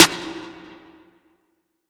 DDK1 SNARE 3.wav